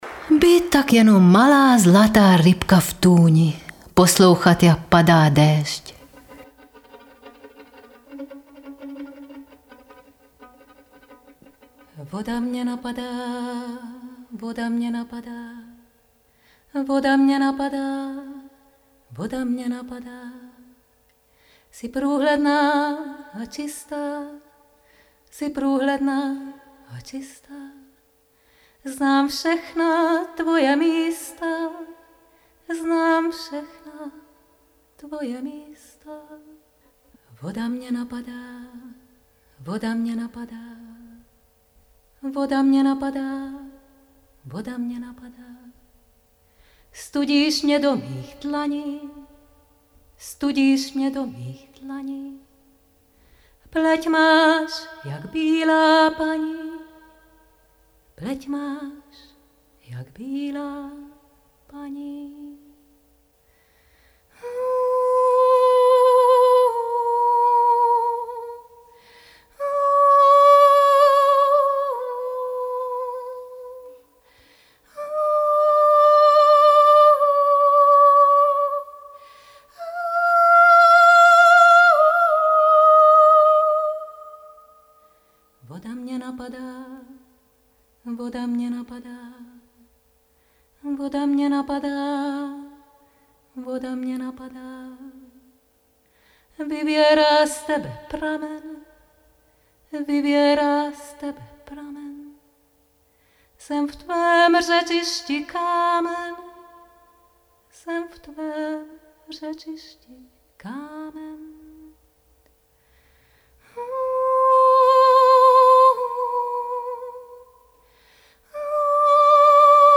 Z workshopu vzešla i písnička